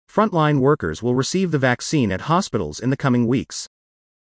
ディクテーション第1問
【ノーマル・スピード】